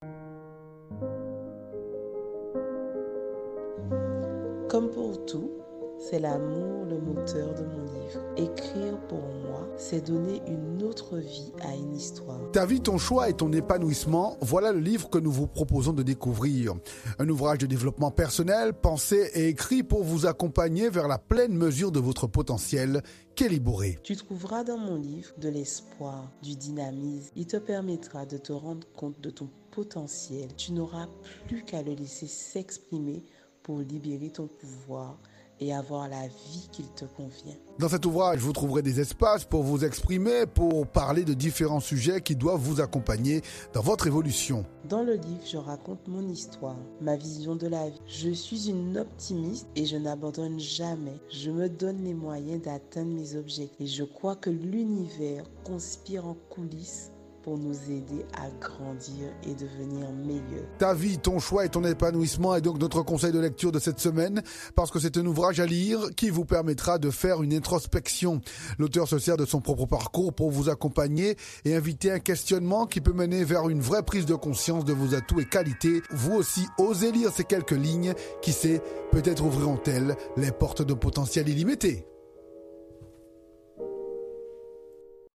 Interviews radio